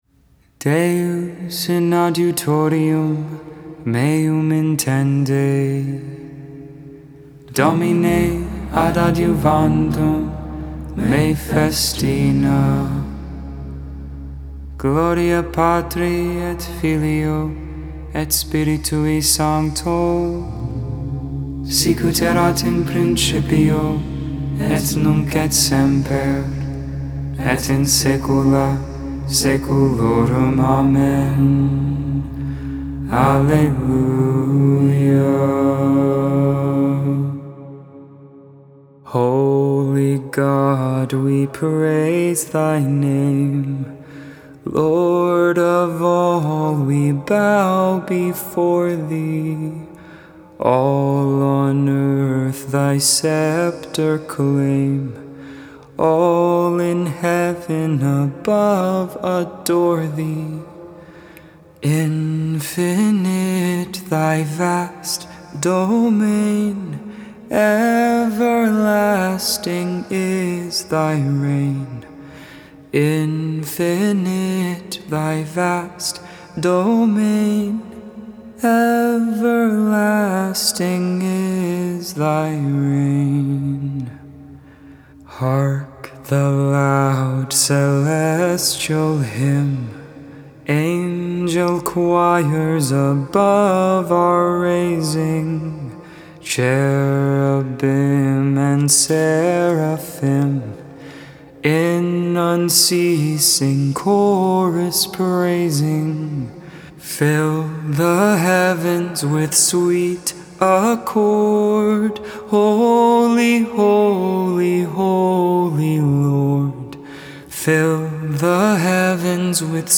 Compline
Hymn